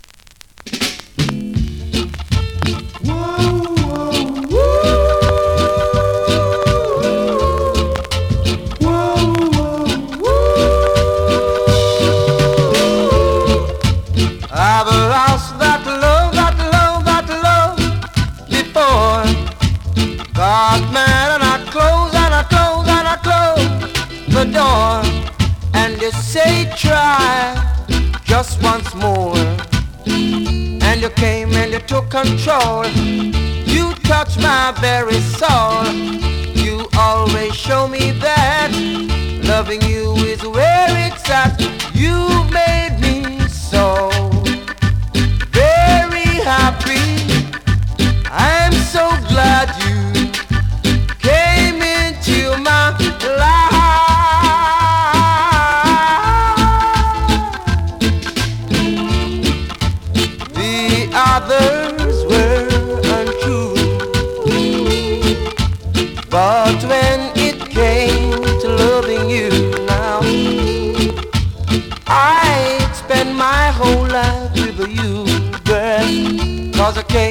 2024!! NEW IN!SKA〜REGGAE
スリキズ、ノイズ比較的少なめで